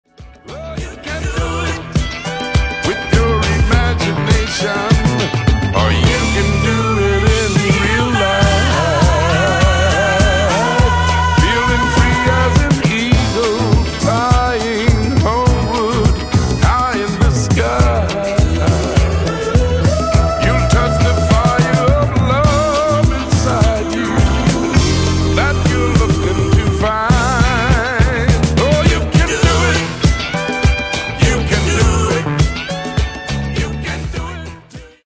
Genere:   Disco | Funky |